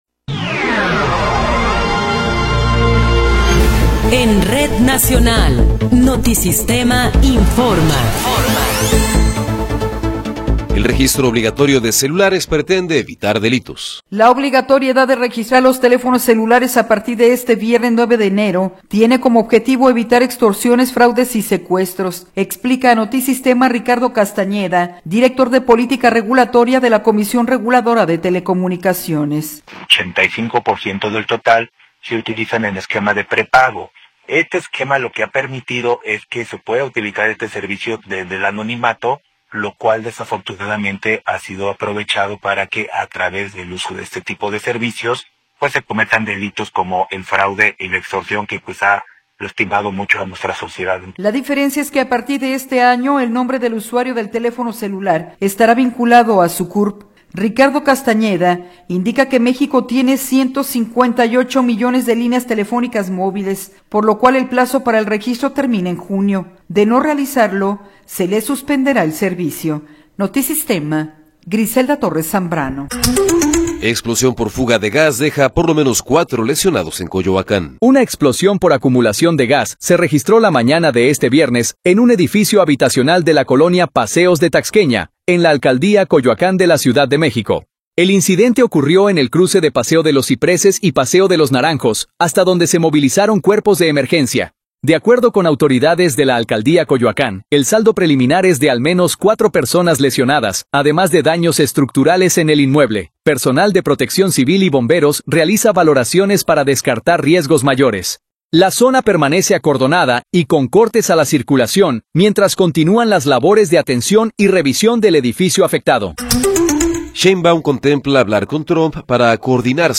Noticiero 12 hrs. – 9 de Enero de 2026
Resumen informativo Notisistema, la mejor y más completa información cada hora en la hora.